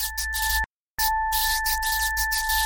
简单的黑暗低音与高和弦
描述：非常简单但却很好听的黑暗低音，有一个不合拍的高音和弦。
标签： 90 bpm Electronic Loops Bass Loops 459.42 KB wav Key : Unknown
声道立体声